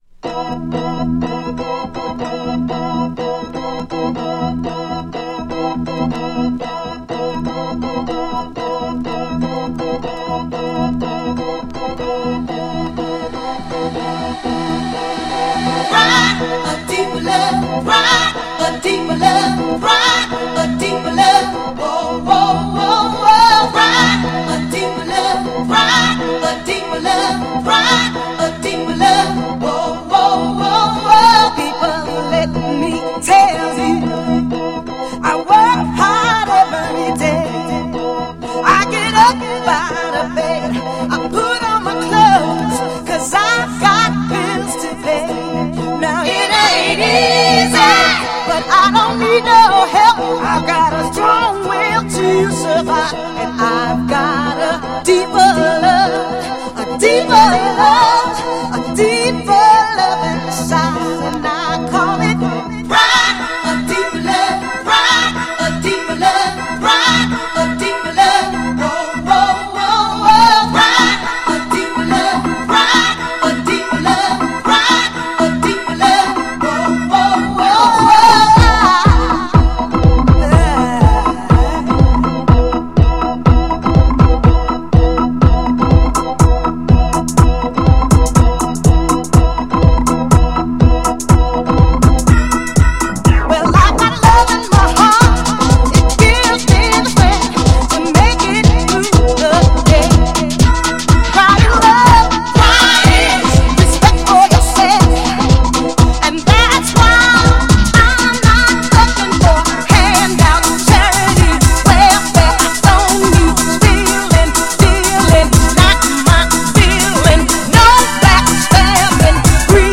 HOUSE CLASSIC!!
GENRE House
BPM 126〜130BPM
熱いボーカル